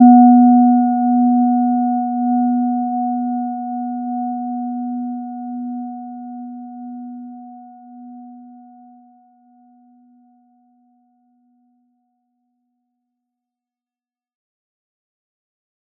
Gentle-Metallic-1-B3-mf.wav